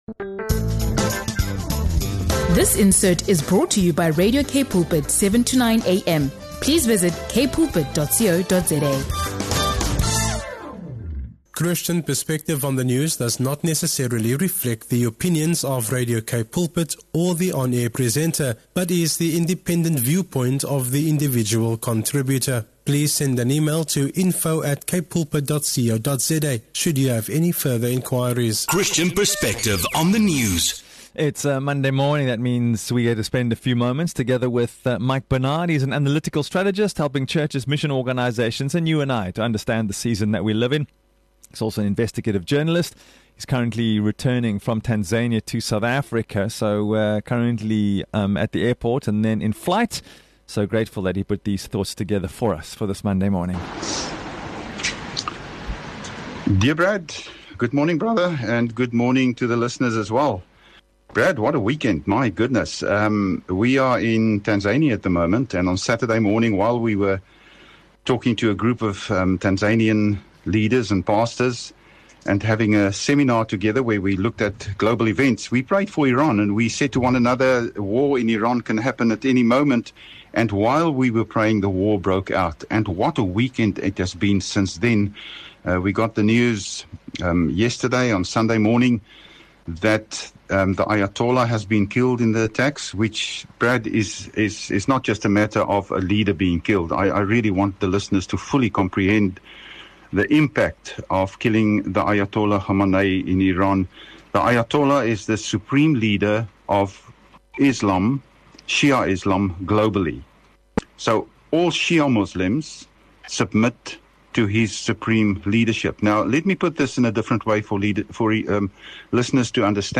He also explores the broader impact on Christian missions, the witness of the Gospel in the region, and why Christians around the world are called to pray for peace, for the church, and for the people of Iran. Listen to this in-depth conversation about geopolitics, faith, and the challenges facing the church in the Middle East.